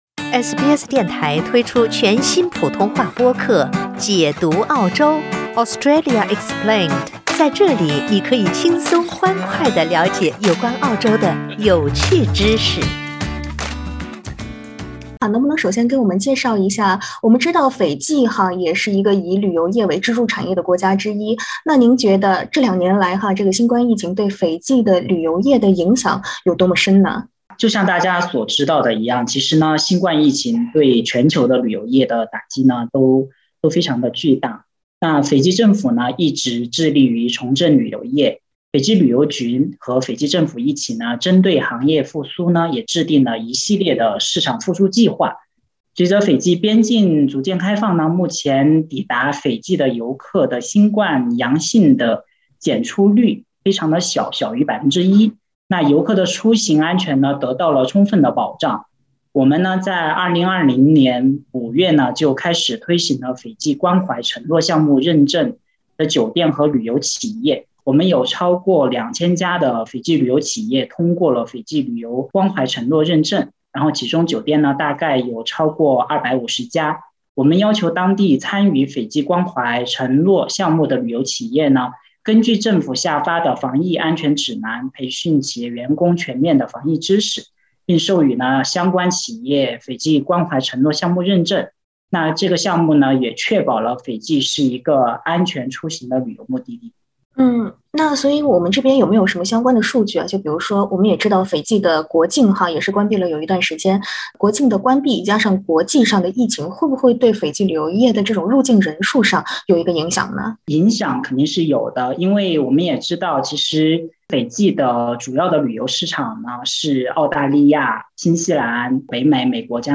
（點擊上圖收聽寀訪）